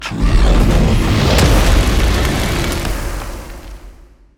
spell-impact-2.mp3